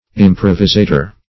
Improvisator \Im*prov"i*sa`tor\, n.